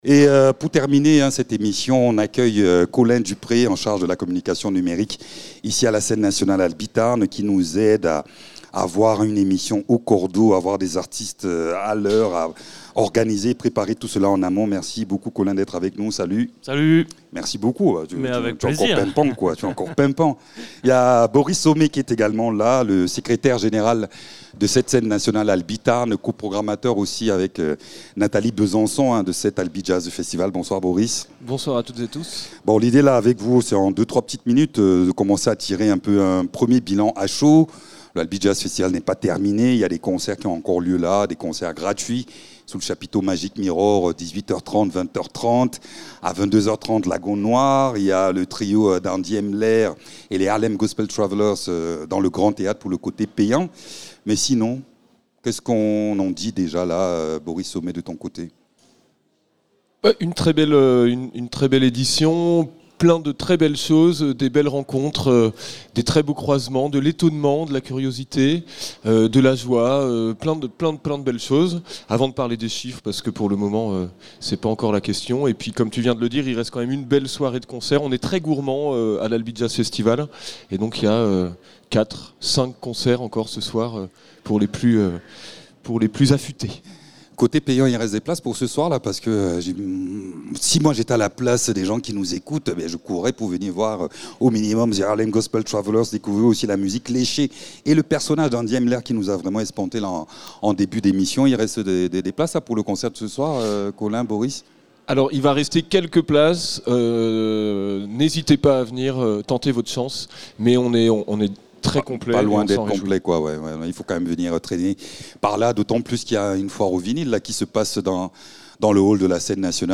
Invité(s)